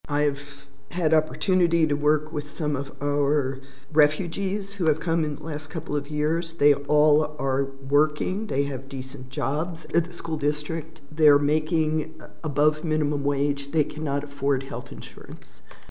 Commissioner Susan Adamchak disagreed, saying Medicaid expansion would directly impact Manhattan’s working poor.